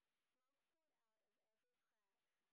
sp13_white_snr30.wav